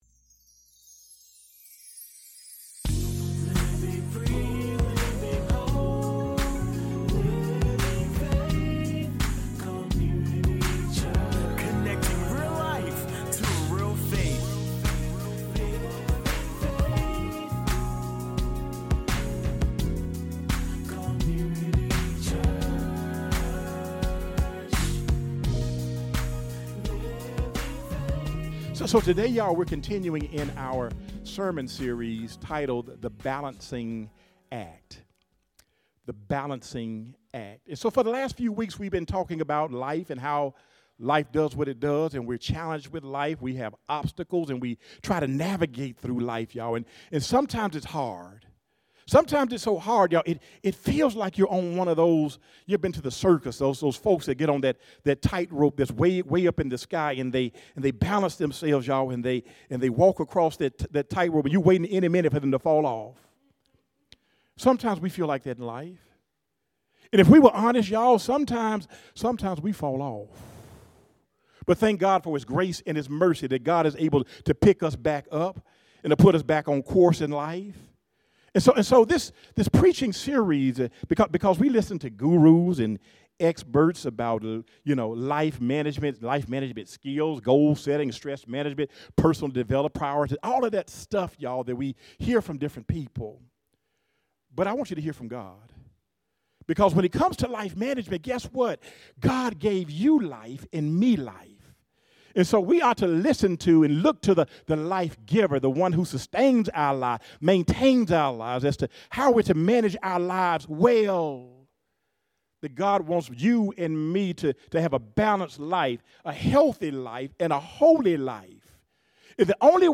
3-23-25 “It’s About Time!” - Sermon